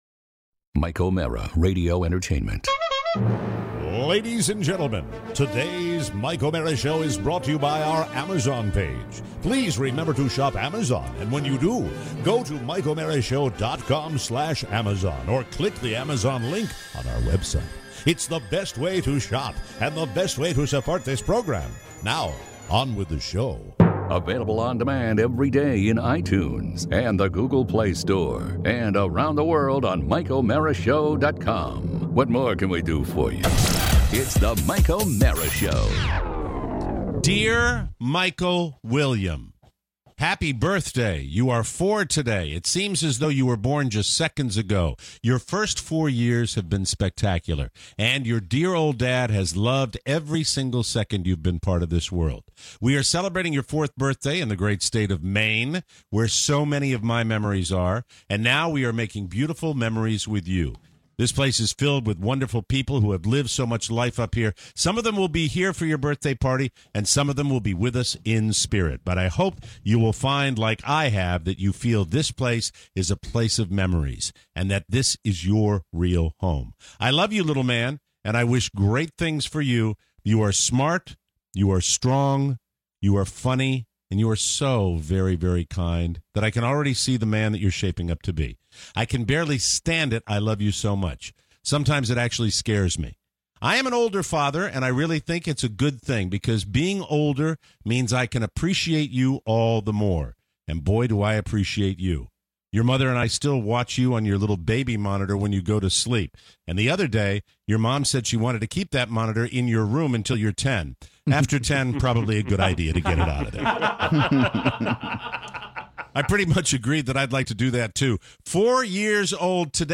Your calls today… international even! Plus… a special birthday, mixed emotions, and a very curious smell.